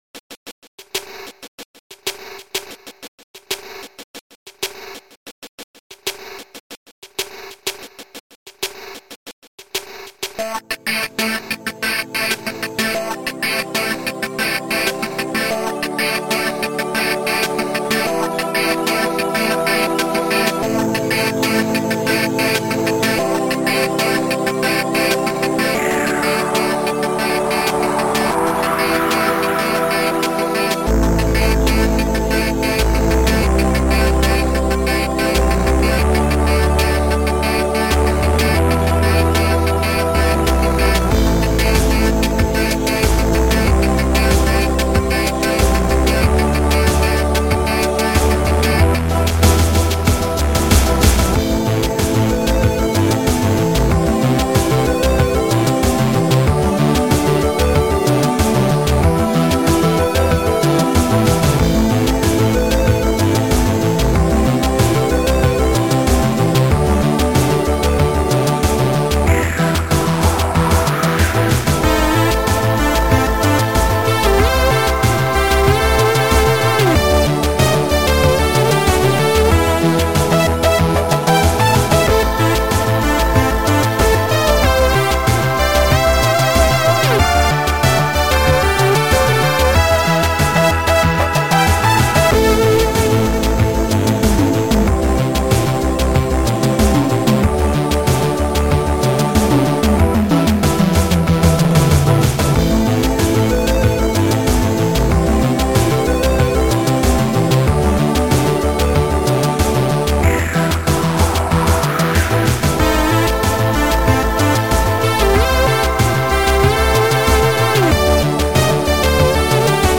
Sound Format: Noisetracker/Protracker
Sound Style: Synth / Mellow